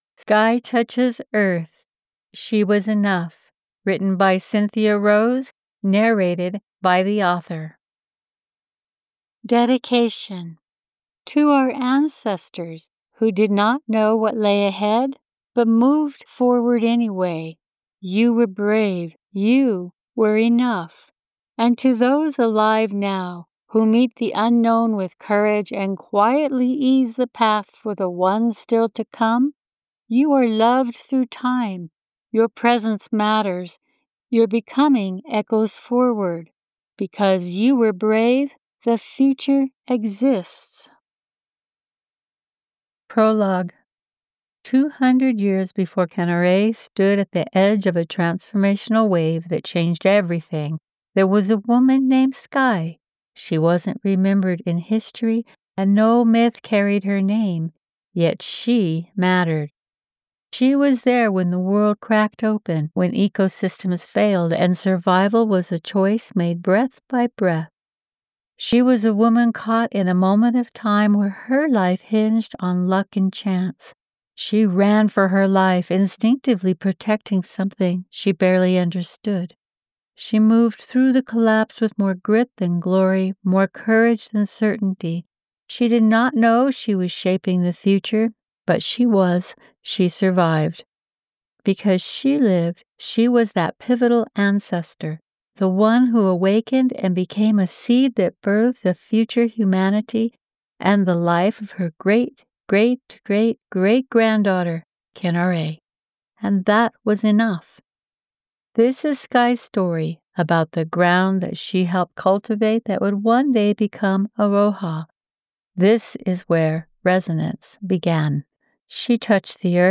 Listen to the opening of the audiobook while you're here.